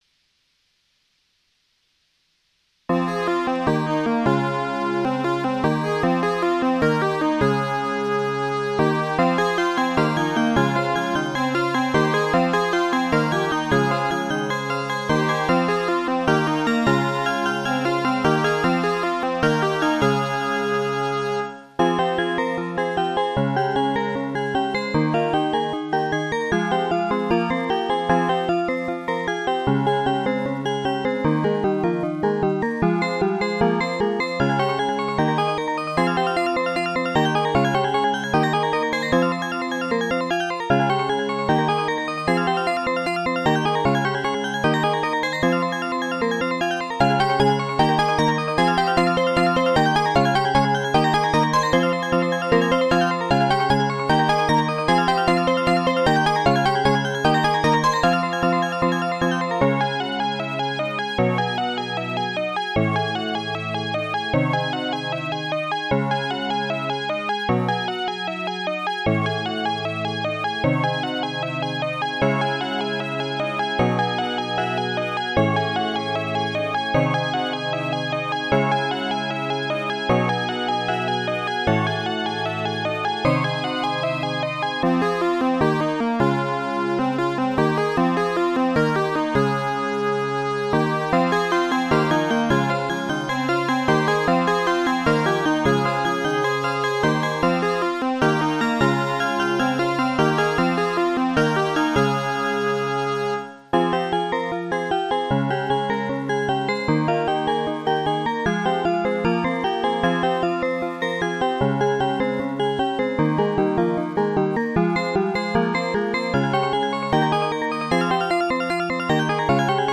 如下是音源通过AG03声卡内录的效果